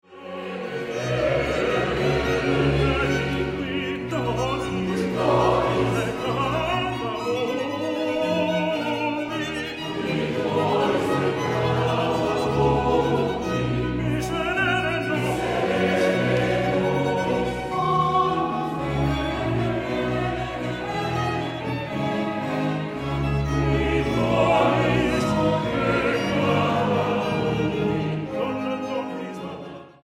Música Barroca Mexicana